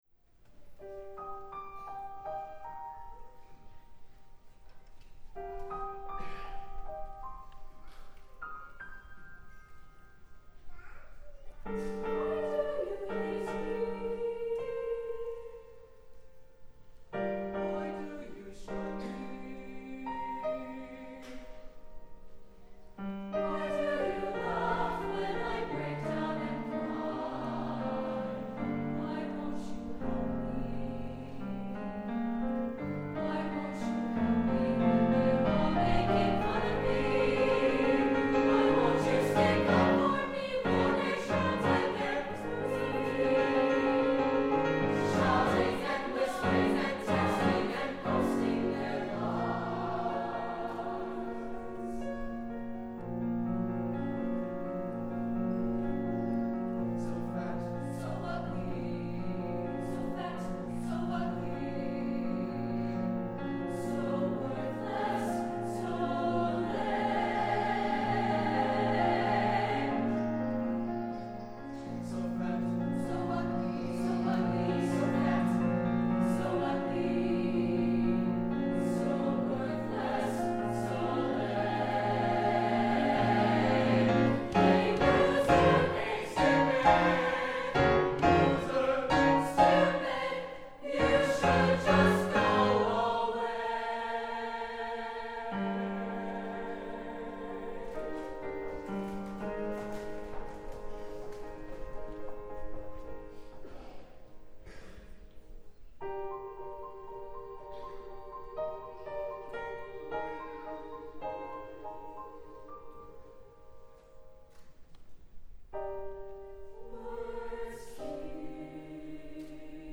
SATB, piano